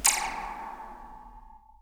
zap1_v1.wav